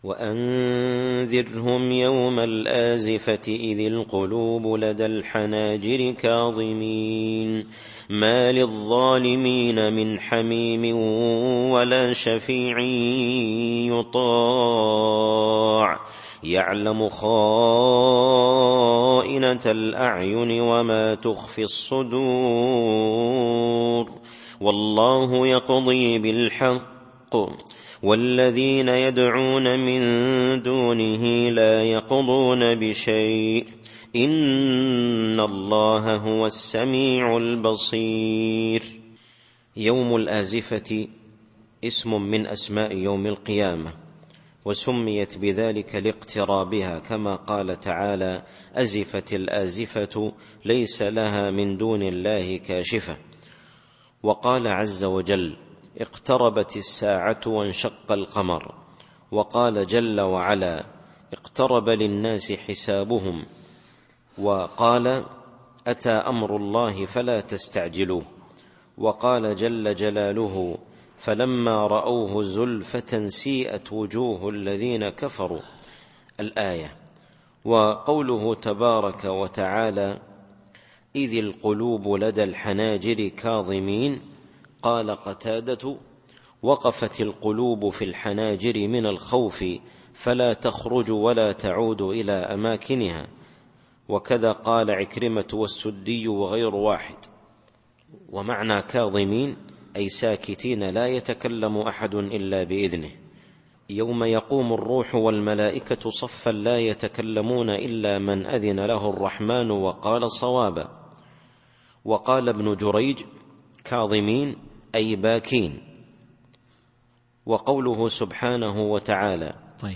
التفسير الصوتي [غافر / 18]